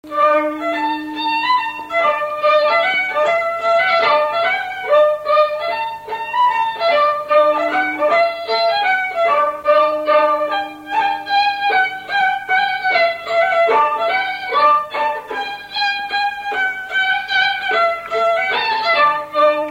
Mazure
Résumé instrumental Usage d'après l'analyste gestuel : danse
Pièce musicale inédite